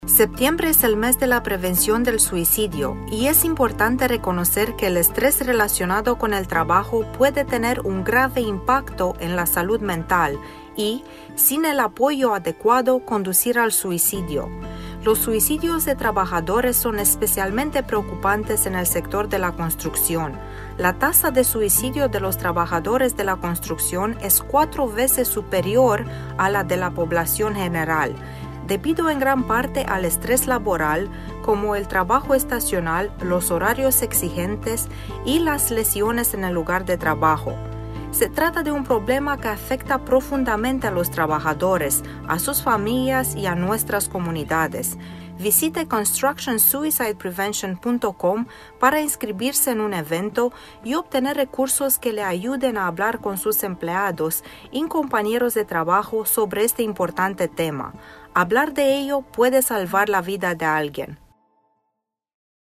PSA_suicide_prevention_spanish_2022.mp3